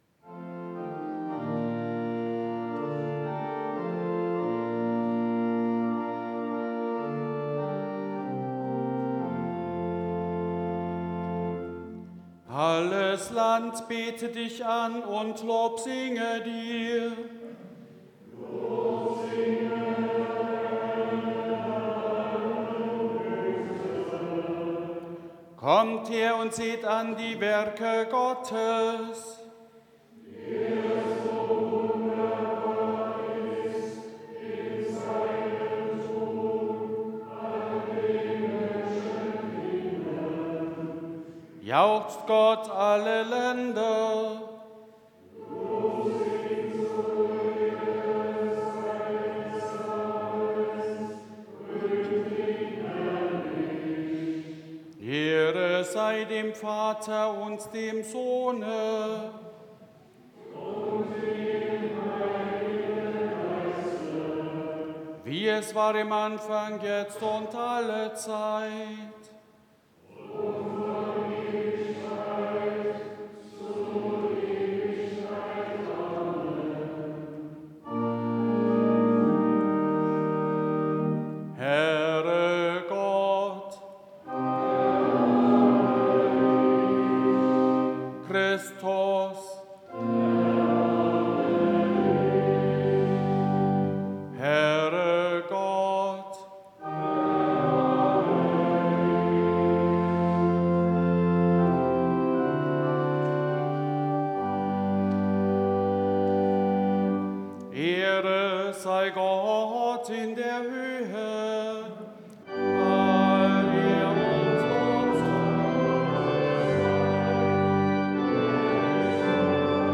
3. Eingangsliturgie Ev.-Luth.
Audiomitschnitt unseres Gottesdienstes vom 2. Sonntag nach Epipanias 2026.